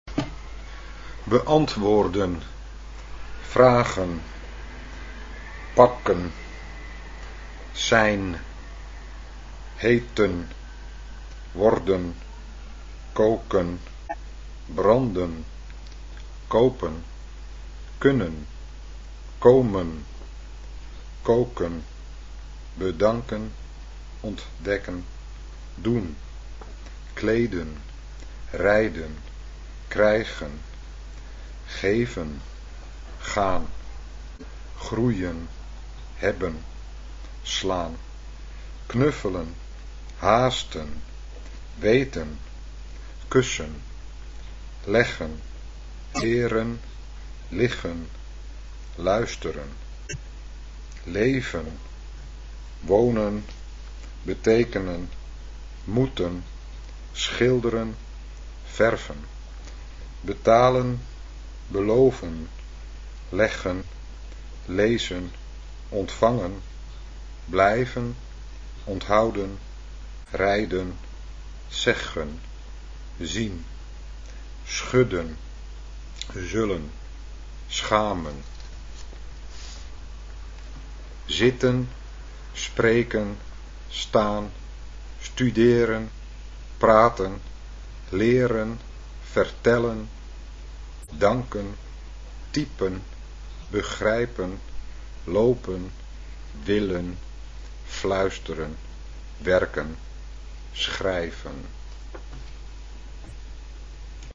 sound sample, how to pronouns the verbs in that middle column :-)) ... (419 Kb)